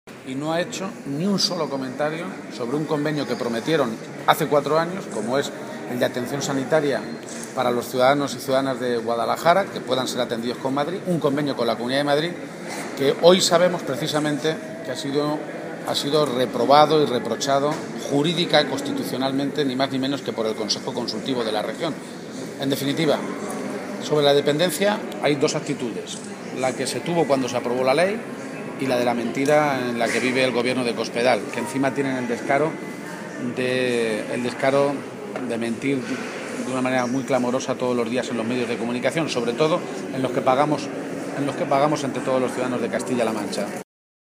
Lo hacía mientras atendía a los medios de comunicación, minutos antes de la inauguración, en la Casa de la Cultura del municipio azudense, de la exposición, «La Lucha por la Dignidad».